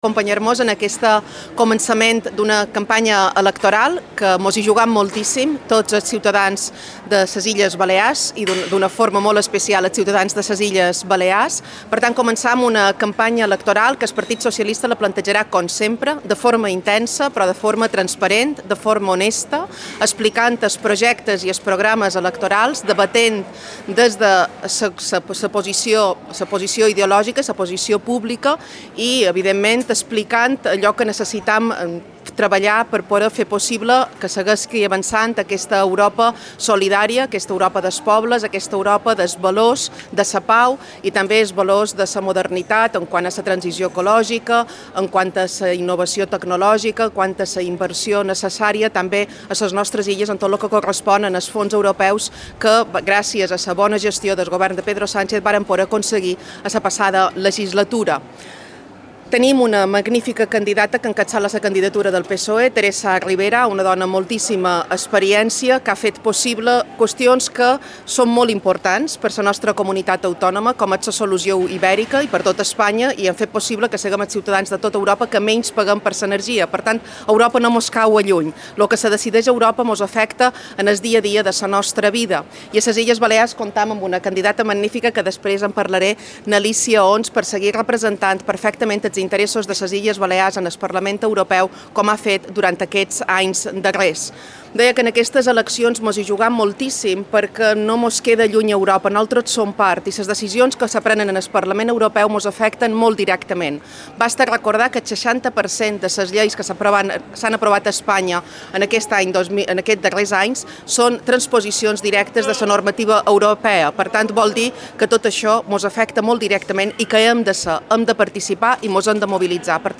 La secretària general del PSIB i presidenta del Congrés dels Diputats, Francina Armengol acompanya la candidata dels socialistes de les illes a la llista europea del PSOE per aquest 9J, Alícia Homs, en l’acte d’inici de la campanya electoral.